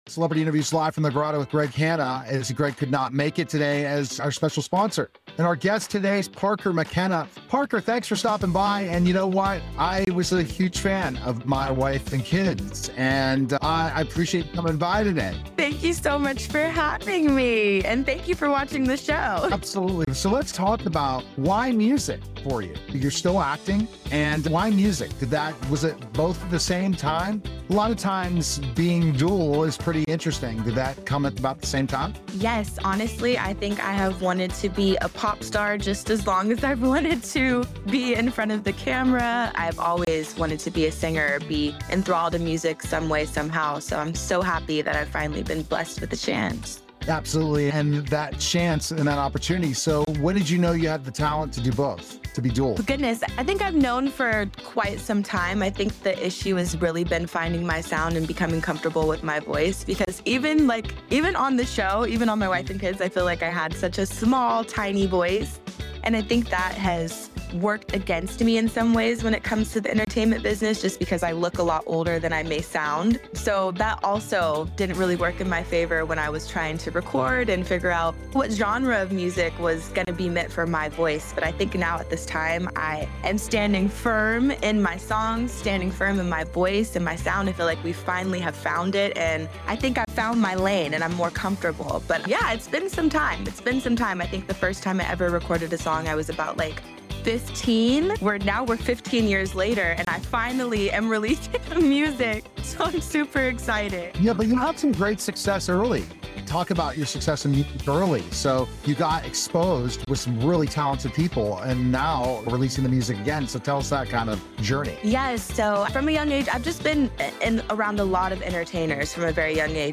actress and singer Parker McKenna for an inspiring conversation about her transition from acting in My Wife and Kids to pursuing her lifelong dream of becoming a pop artist